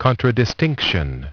Transcription and pronunciation of the word "contradistinction" in British and American variants.